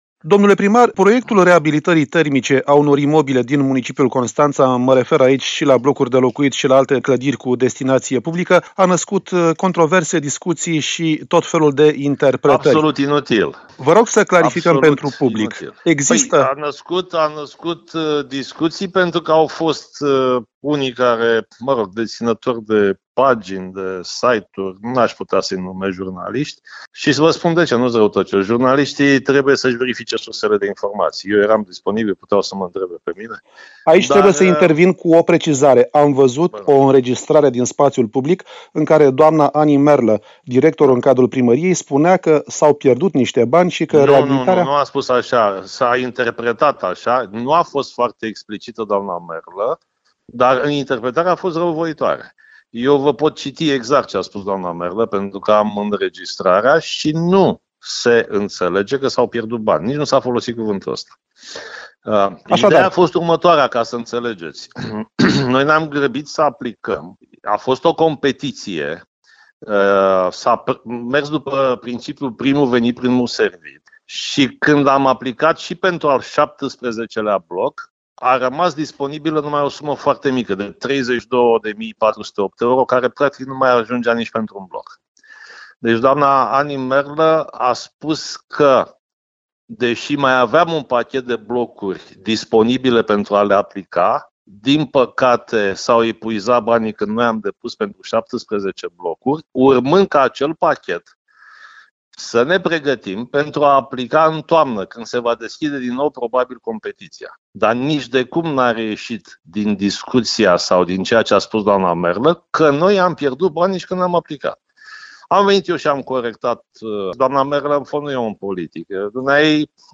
INTERVIU | Primarul Vergil Chiţac, precizări despre programul de reabilitare termică a blocurilor - Știri Constanța - Radio Constanța - Știri Tulcea